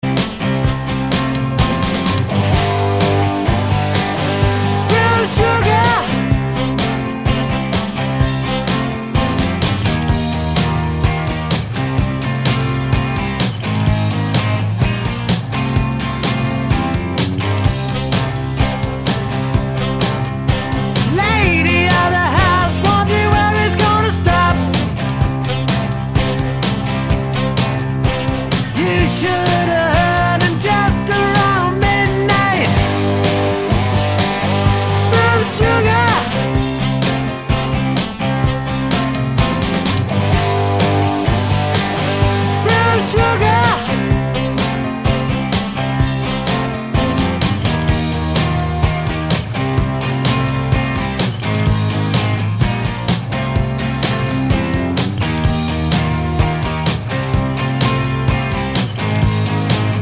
Minus Sax Solo with Backing Vocals Rock 3:51 Buy £1.50